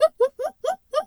Animal_Impersonations
zebra_whinny_05.wav